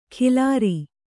♪ khilāri